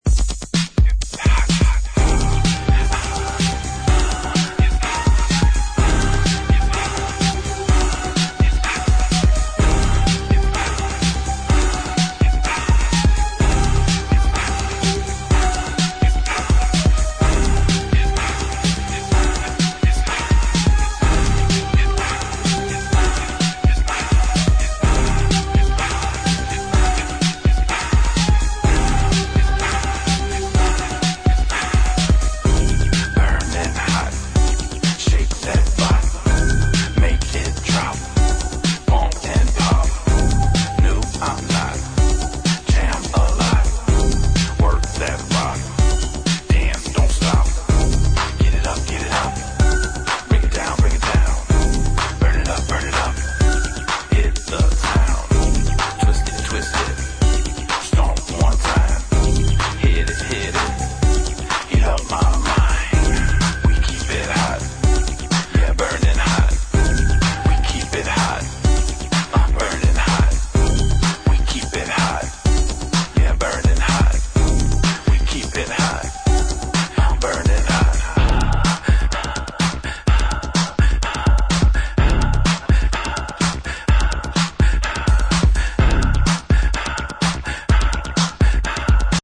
Bass Booms like no other in this song.